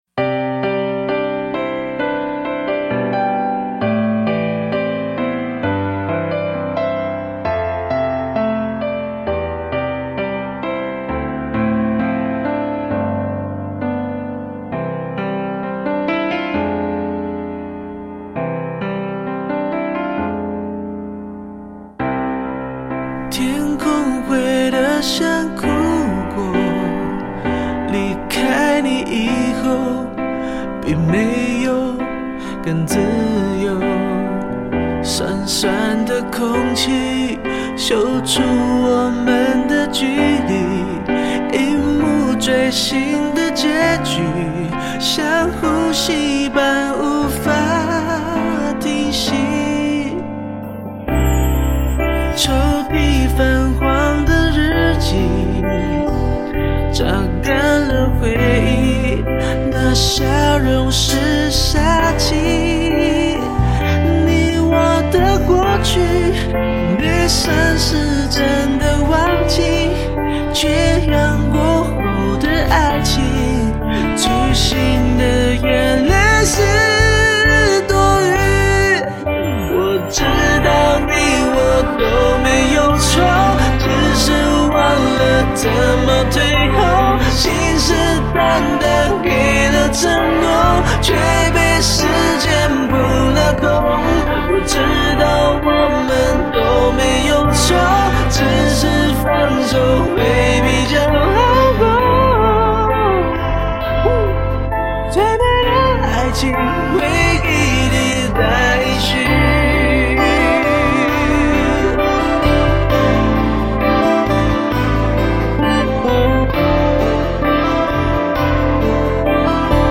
无鼓伴奏